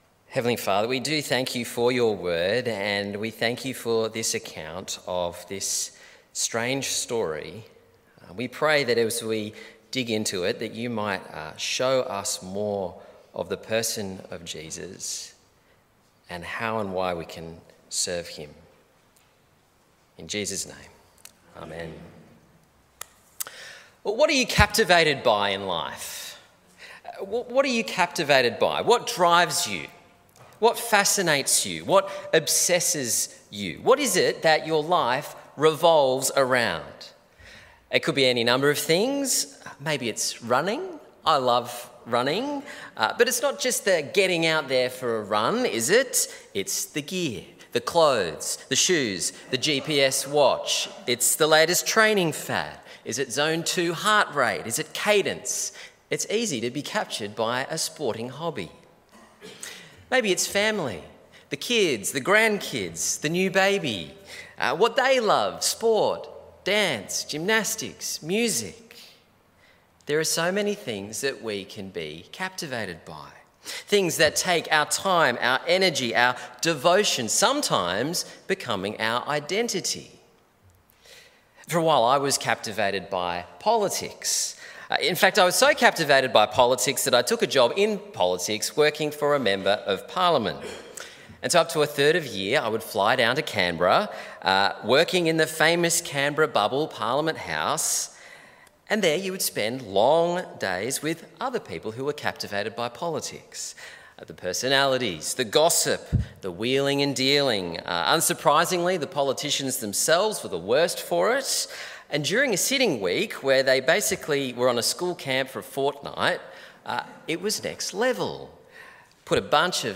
Sermon on Mark 14:1-9 - Anointed
Listen to the sermon on Mark 14:1-9 in our Jesus Unfiltered series.